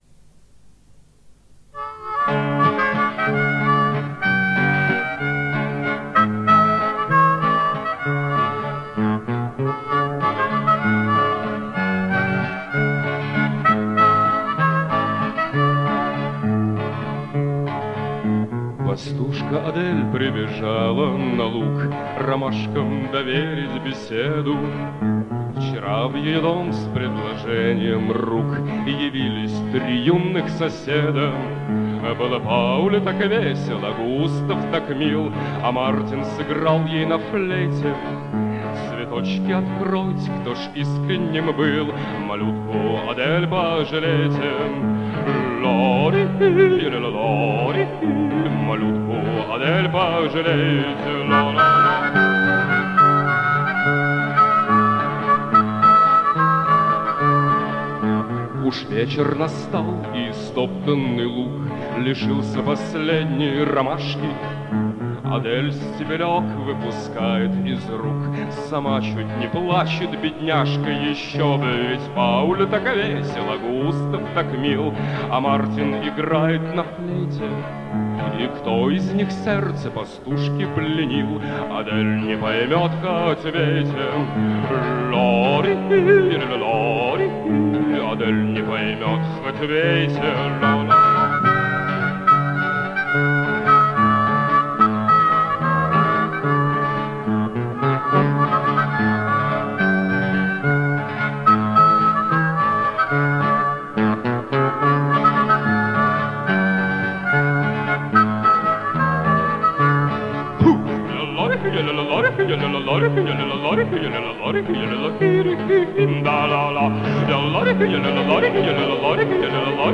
Не знаю какая песня звучала в  передаче, но,  у меня есть  песня, в ней присутствуют   намеки на тирольское  пение
И сама песенка  забавная...